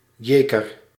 The Jeker (Dutch pronunciation: [ˈjeːkər]
Nl-Jeker.ogg.mp3